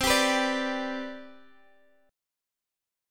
CM7sus2 chord